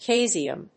音節cae・si・um 発音記号・読み方
/síːziəm(米国英語)/